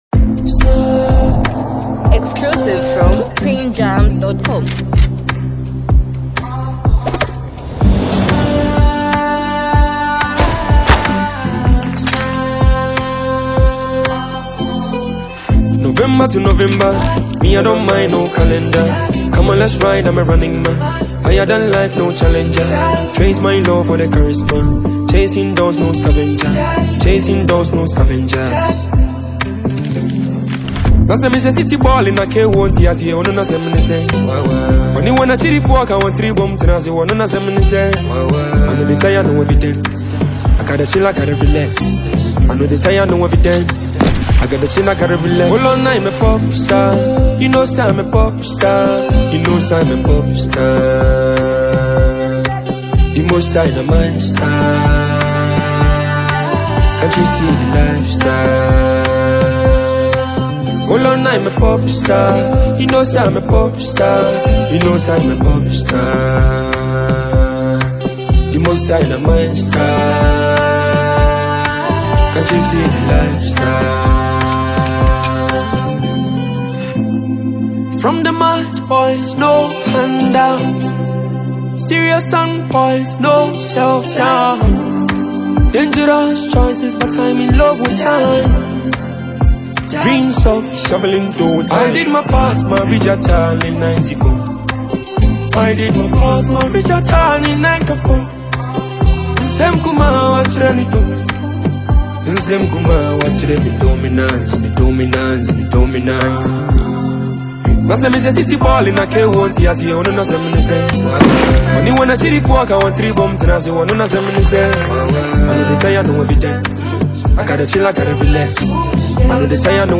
Through heartfelt lyrics and a haunting instrumental